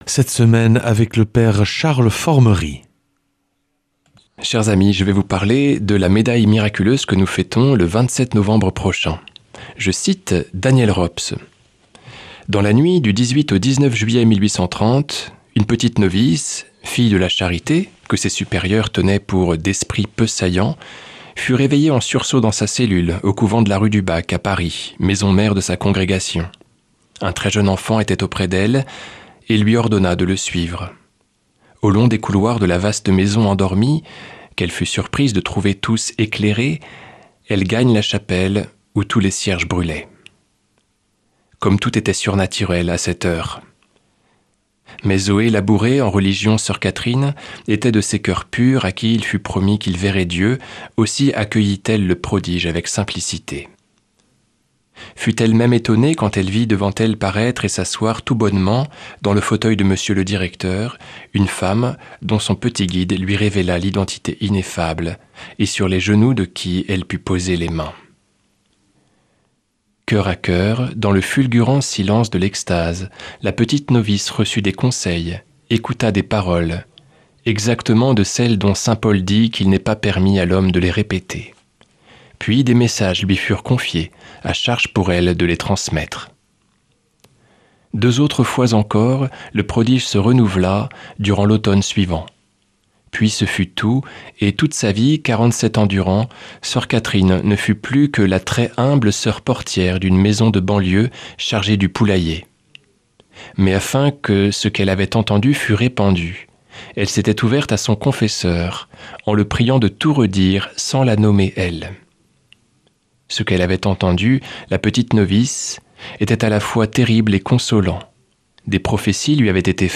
lundi 24 novembre 2025 Enseignement Marial Durée 10 min